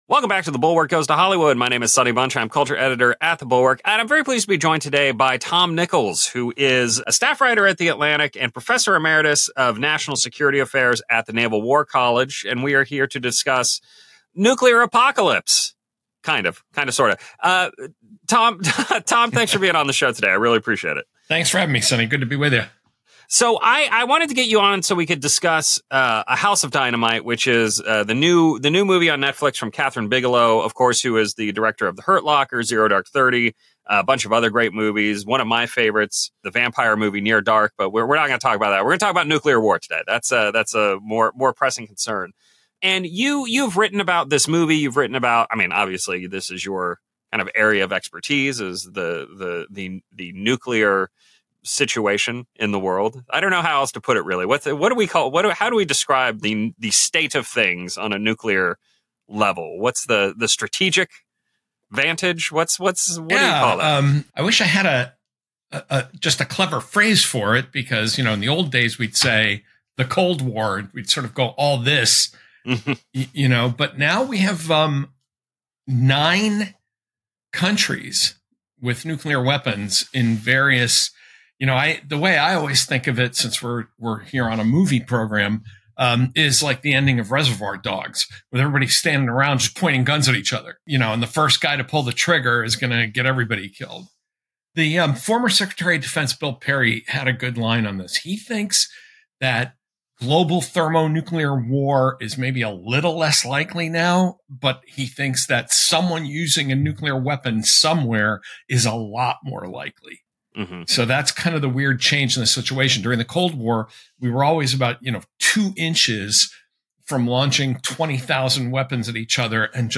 talks with Atlantic writer and national security expert Tom Nichols about the new Netflix thriller A House of Dynamite—the most realistic nuclear movie in decades.